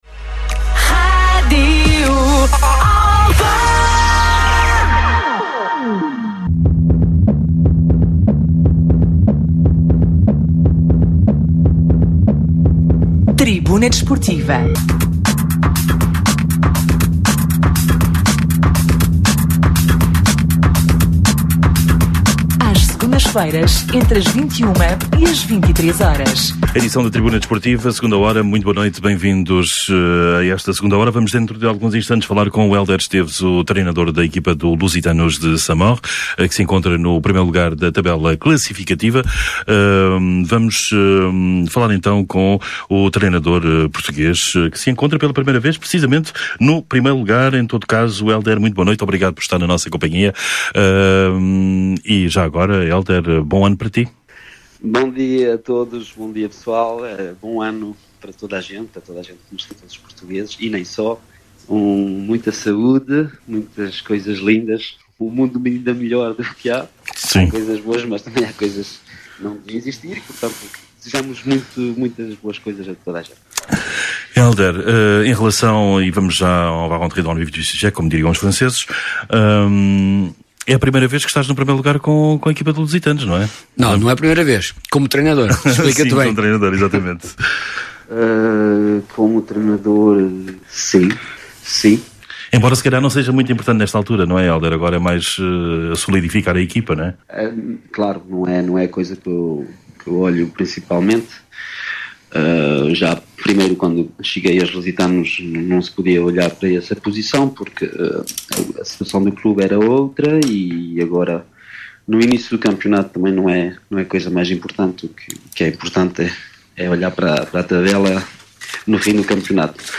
Atualidade Desportiva, Entrevistas, Comentários e Reportagens
Tribuna Desportiva é um programa desportivo da Rádio Alfa às Segundas-feiras, entre as 21h e as 23h.